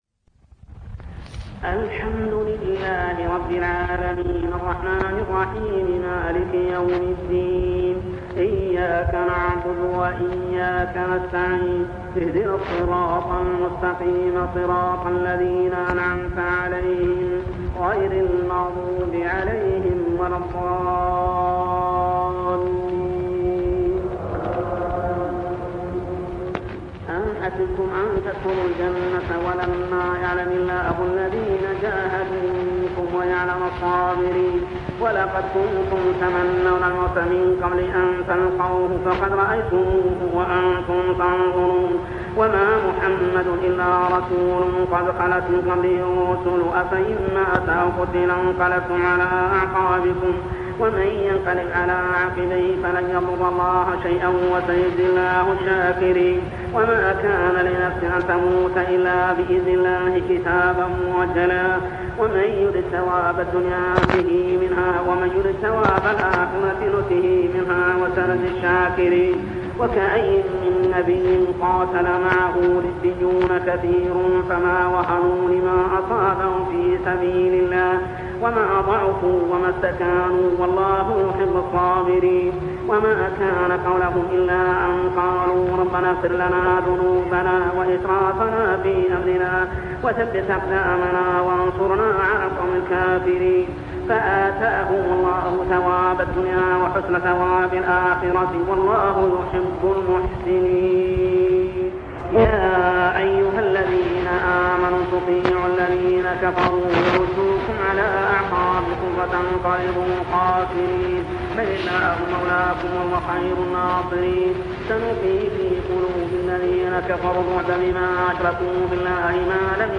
صلاة التراويح عام 1401هـ سورة آل عمران 142-163 | Tarawih prayer Surah Al-Imran > تراويح الحرم المكي عام 1401 🕋 > التراويح - تلاوات الحرمين